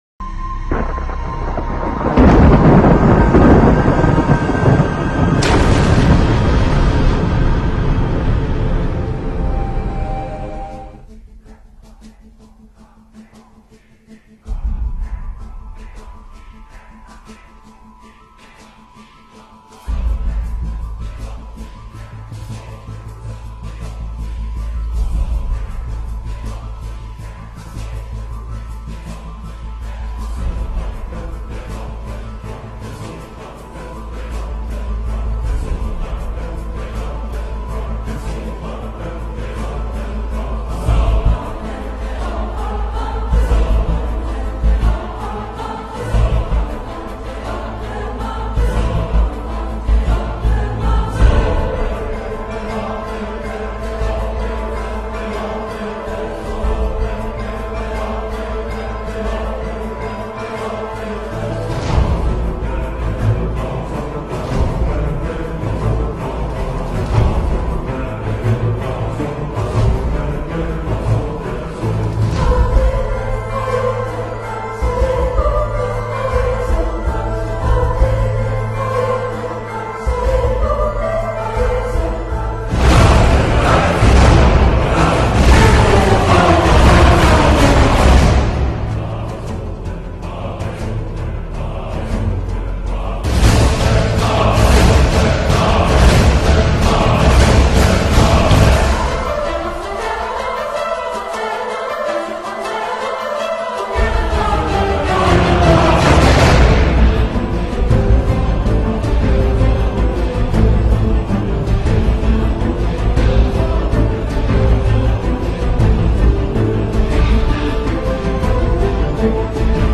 (the music is a bit annoying...and unfortunately the picture quality is no better than this)